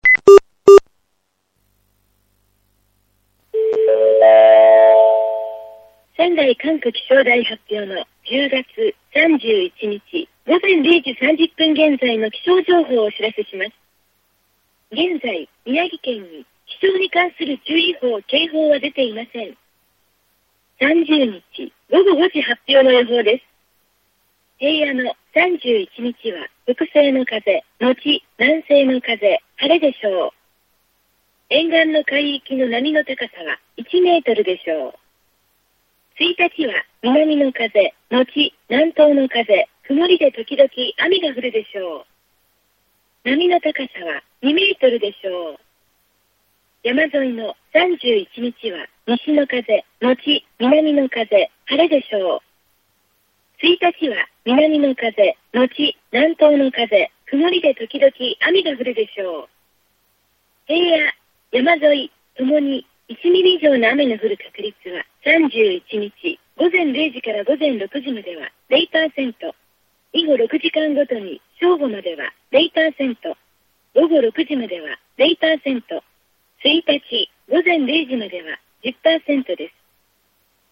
キャリア別通話音質
実際の通話を録音しました。
ガサガサ